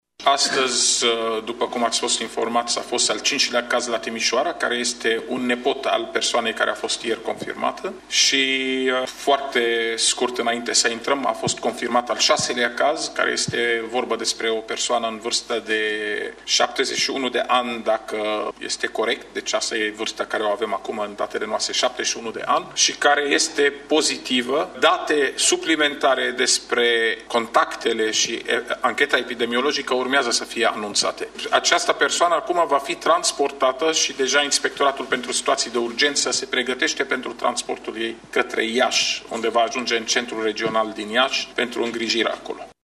Șeful Departamentului pentru Situații de Urgență din cadrul MAI, dr. Raed Arafat: